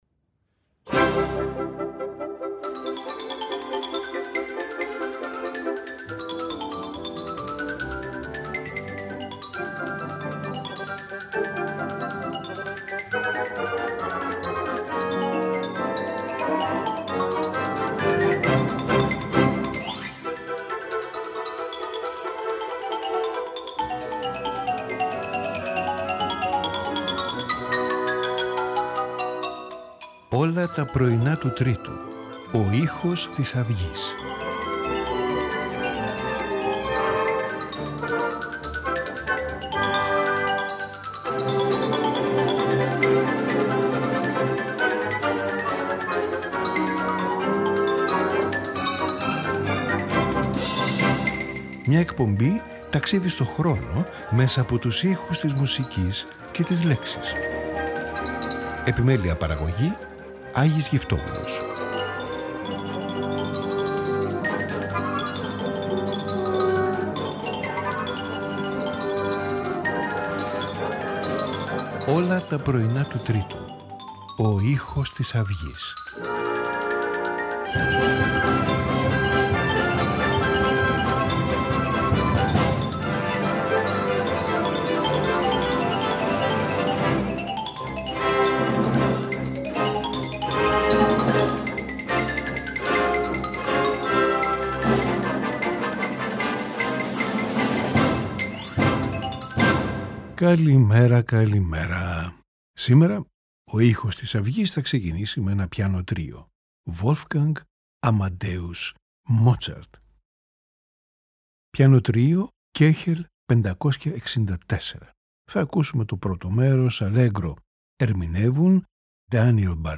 Piano Trio
Violin Concerto
Symphony
Concerto for Two Violins
Piano Concerto